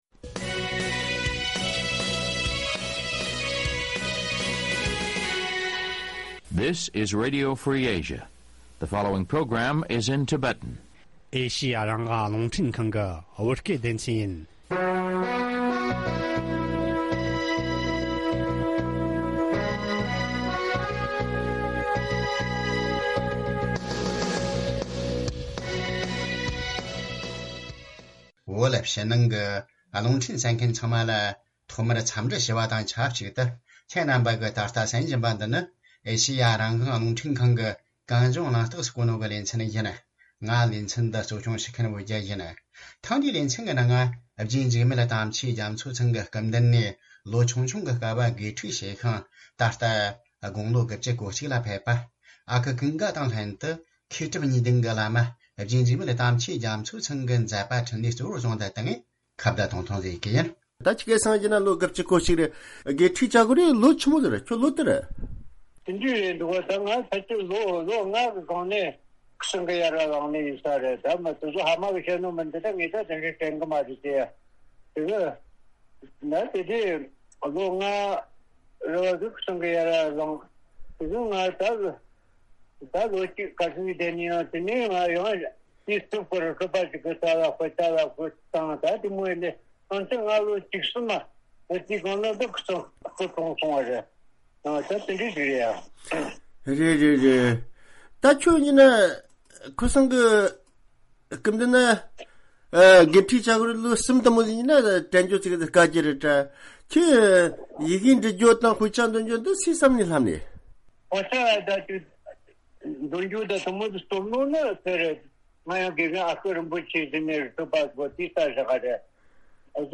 ཁ་བརྡ་ཐུང་ཐུང་ཞིག་བྱས་པ།